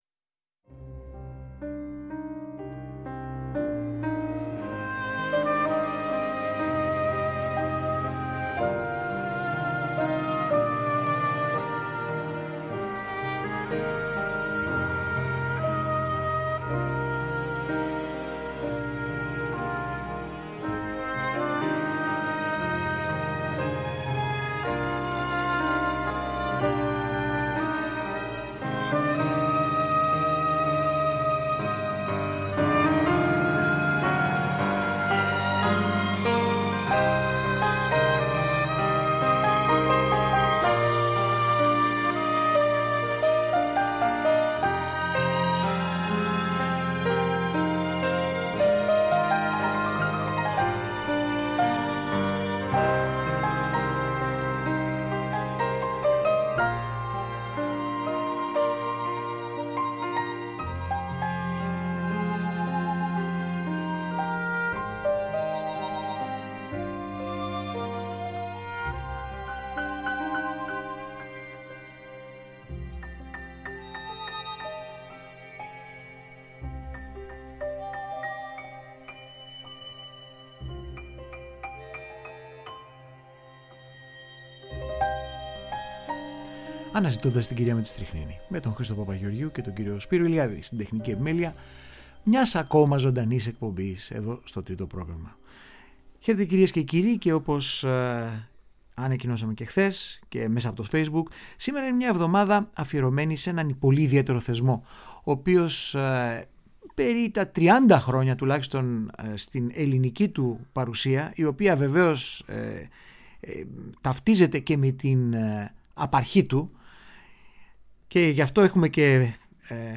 καλεσμένος στο στούντιο